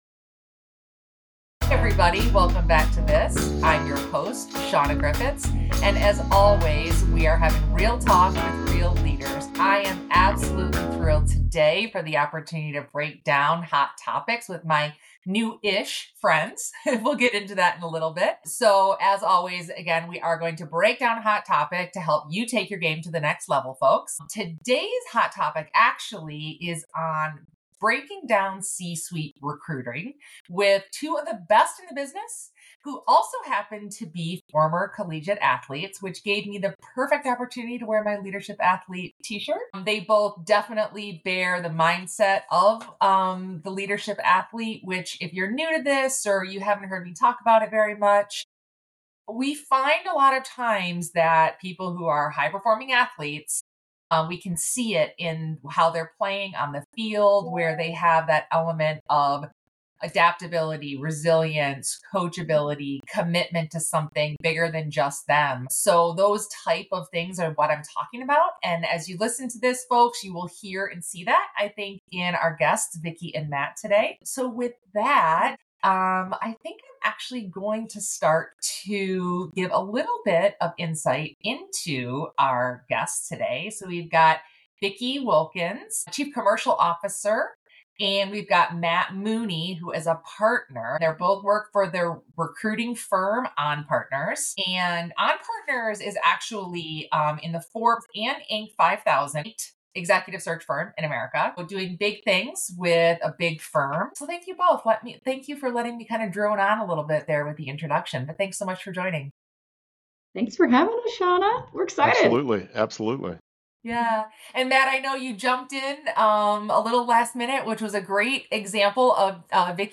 You'll get that sense in this straightforward, informative and authentic conversation.